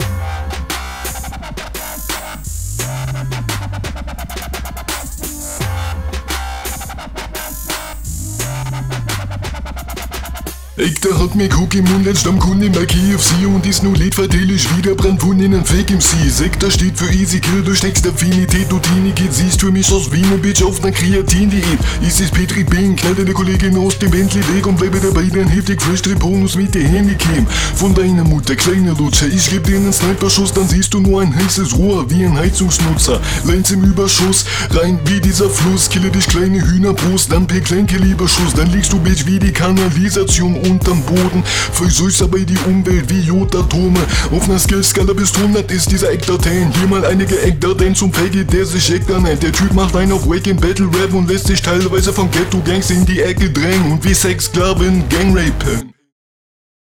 Spongebozz Beat - check.
Mische ausbaufähig.